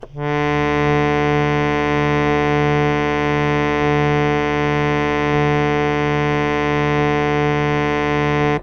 harmonium
D2.wav